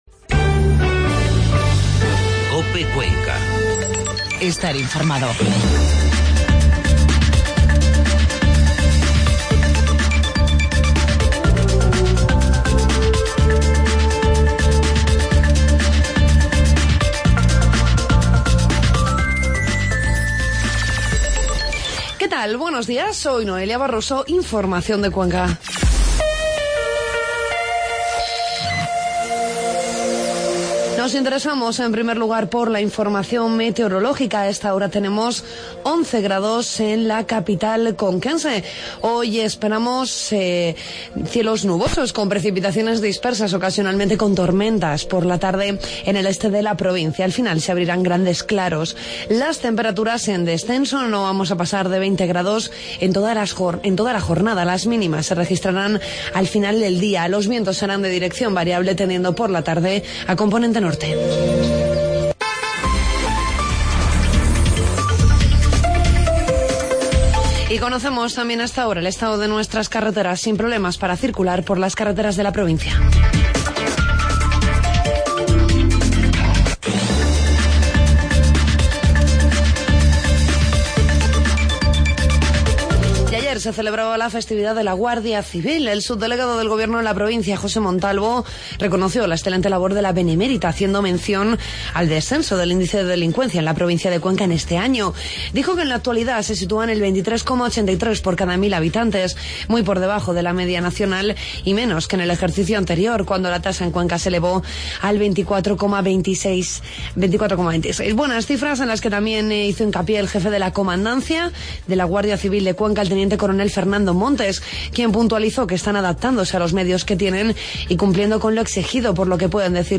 Informativo matinal martes 13 de octubre